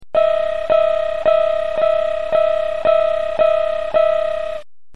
「踏切
ポイント：１弦の１２フレットと２弦の１６フレットというとても高いところを押さえるので、音がびびらないよう注意が必要!!
fumikiri.mp3